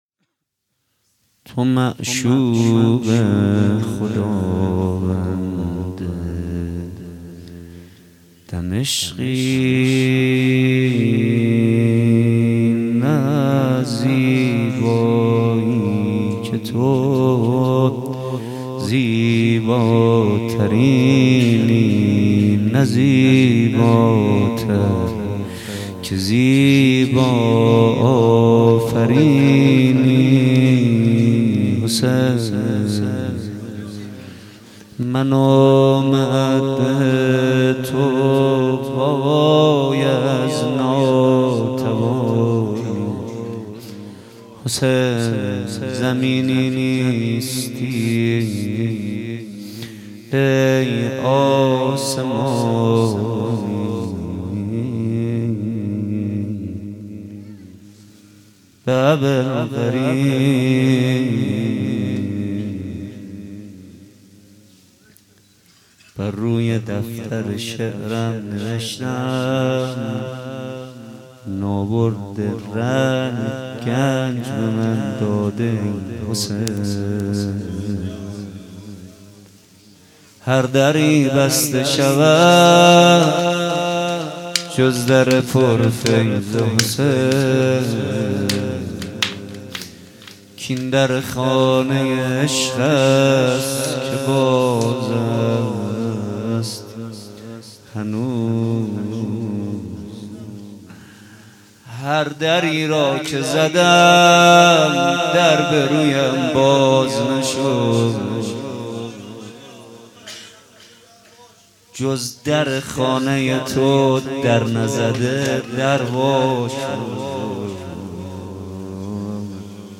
بيست و هفتم صفر 95 - روضه